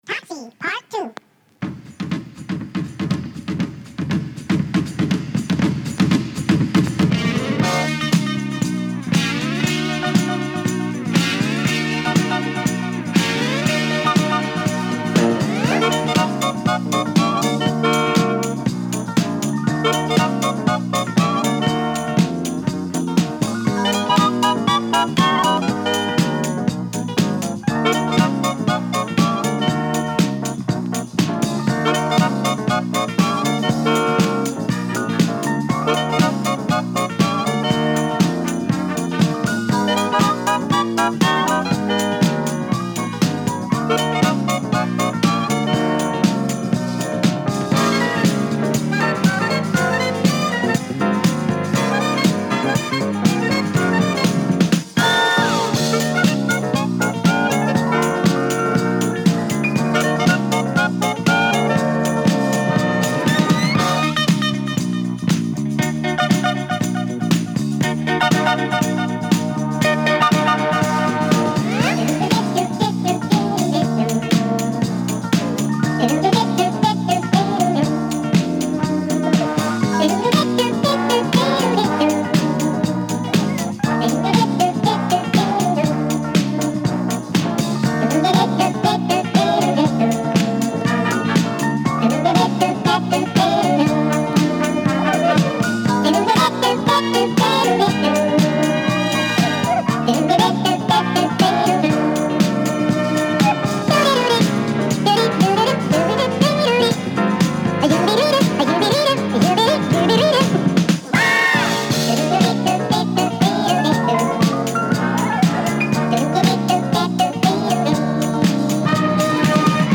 ジャジー・カルト・ディスコ 試聴
ムシ声が入る妖艶なキャバレー仕立てのカルト・ディスコ
アルバムと違い冒頭はドラムブレイクで始まるエディットVer.です。中盤にもドラムブレイクあり。